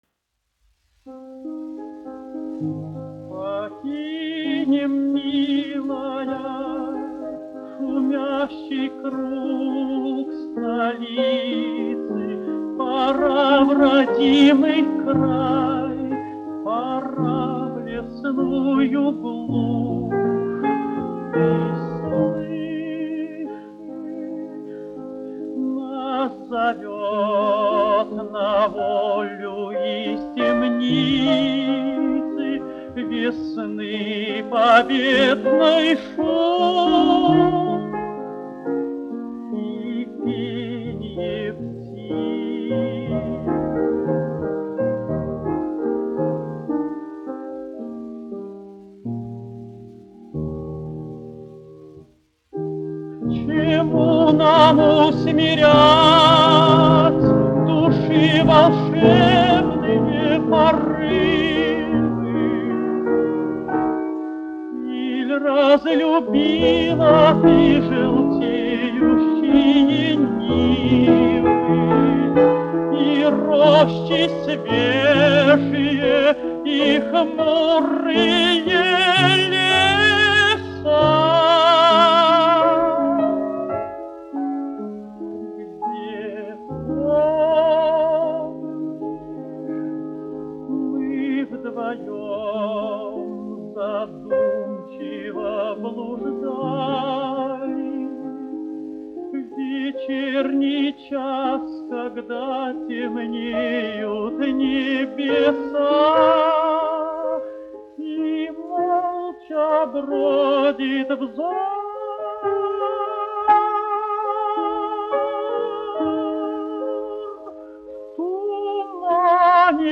Виноградов, Георгий Павлович, 1908-1980, dziedātājs
Гольденвейзер, Александр Борисович, 1875-1961, instrumentālists
1 skpl. : analogs, 78 apgr/min, mono ; 25 cm
Dziesmas (augsta balss) ar klavierēm
Romances (mūzika)
Latvijas vēsturiskie šellaka skaņuplašu ieraksti (Kolekcija)